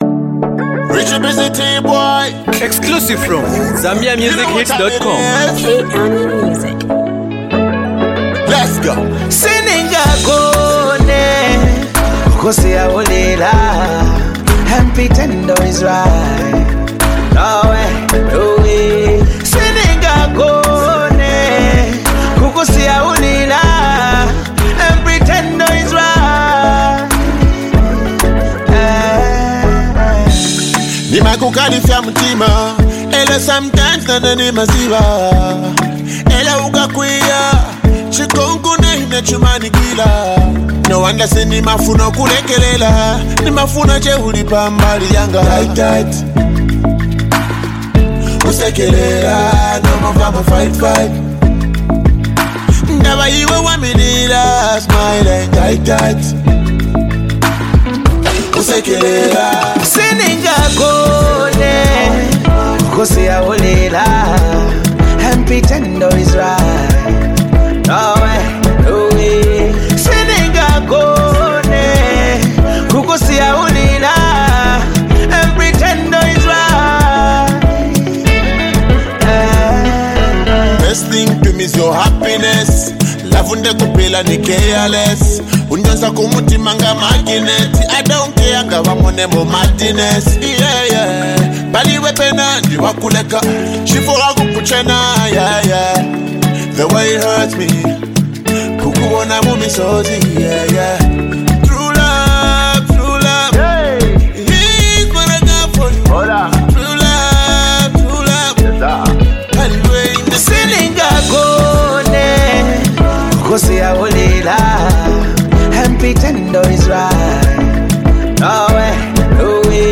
powerful melody